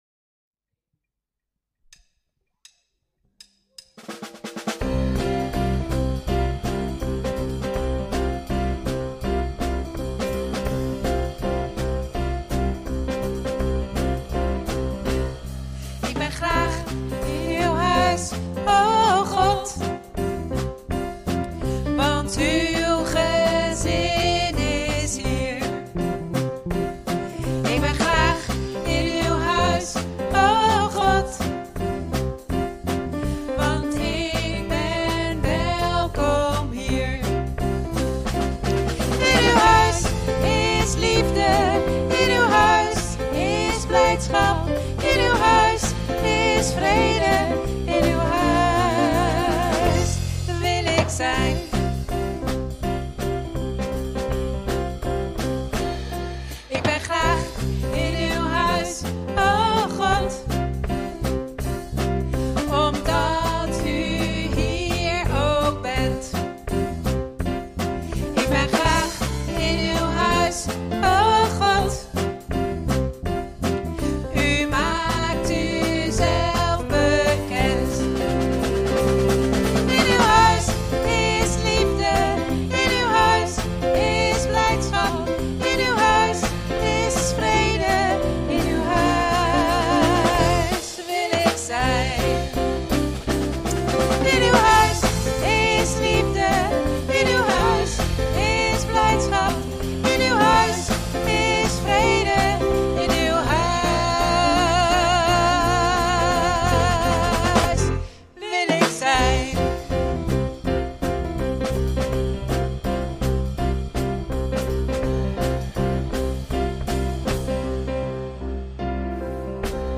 Toespraak 10 mei: Vrucht van de Geest (deel 1) - De Bron Eindhoven